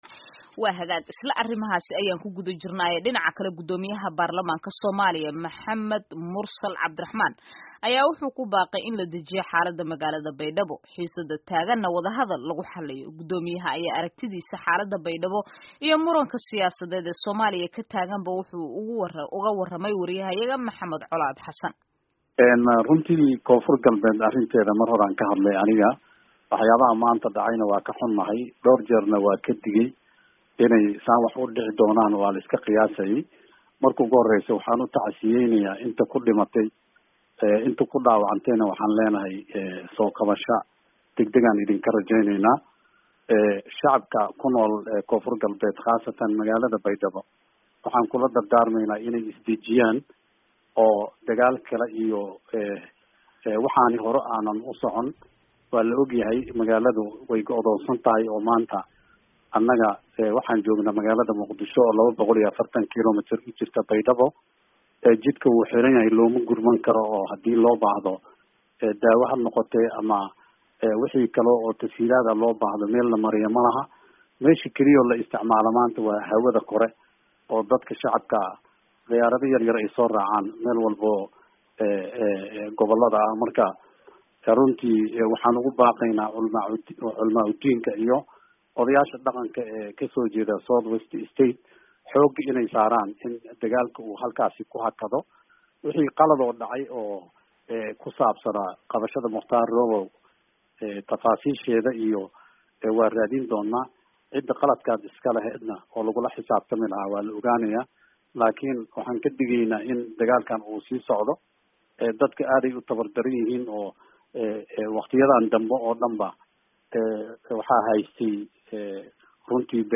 Wareysi: Gudoomiyaha Baarlamanka Soomaaliya
Afhayeenka baarlamanka Soomaaliya Maxamed Mursal Sheekh Cabdiraxmaan ayaa idaacadda VOA siiyey wareysi dhinacyo badan taabanaya, xilli uu gacanta ku hayo mooshin ka dhan ah madaxweynaha Soomaaliya.